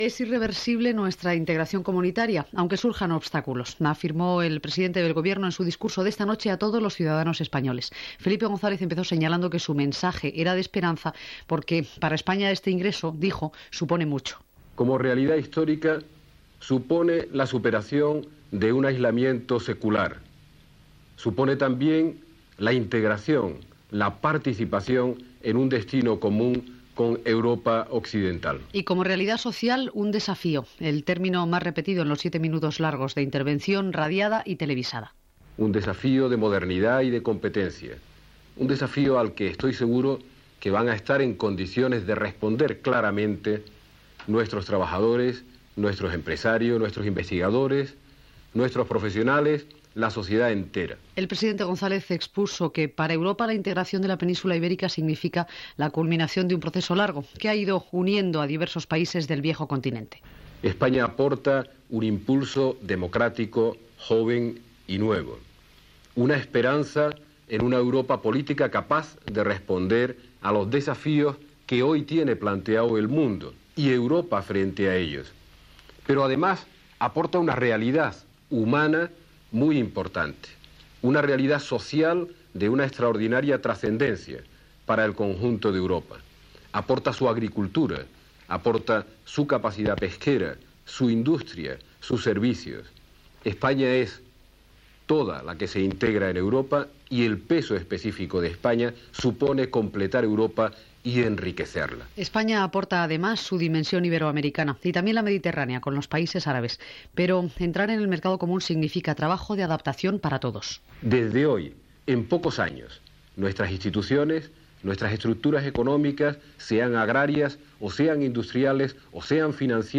Tancament de la negociació per a l'entrada d'Espanya a la Unió Europea, declaracions del president del govern espanyol Felipe González.
Informatiu